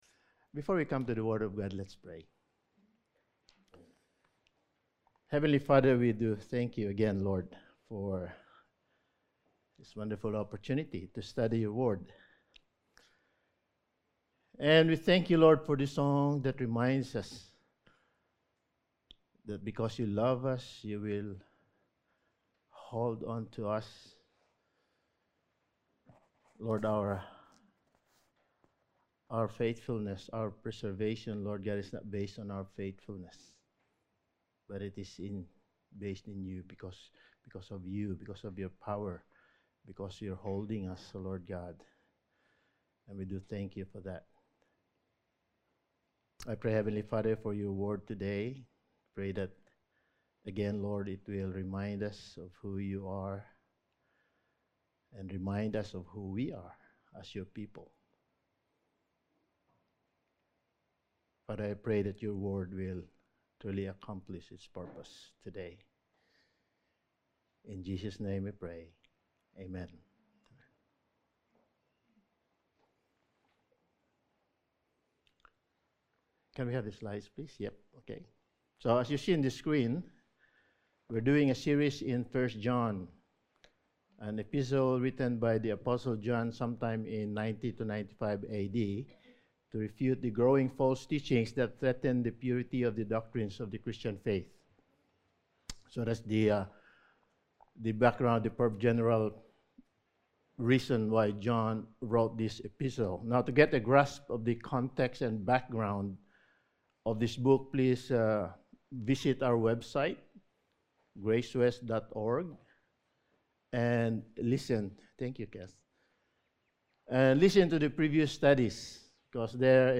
1 John Series – Sermon 8: AntiChrists Exposed
Service Type: Sunday Morning